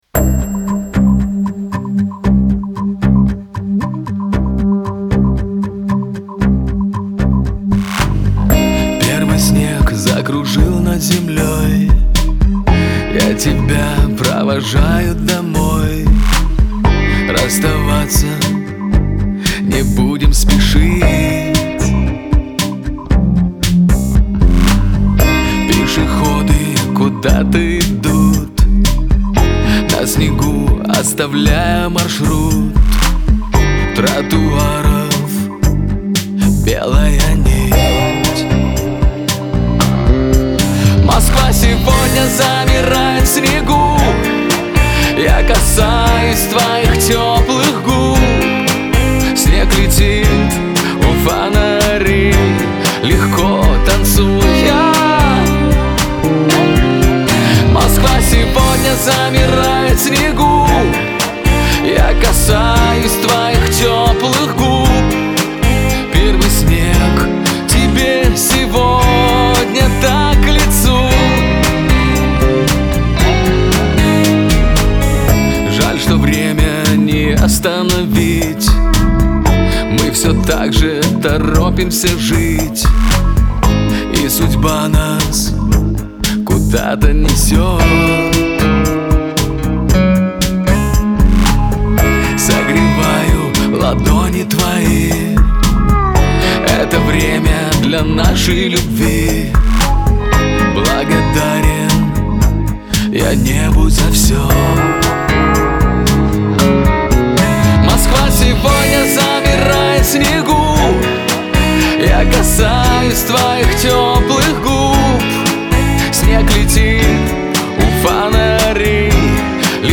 pop , диско